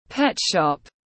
Pet shop /pet ʃɒp/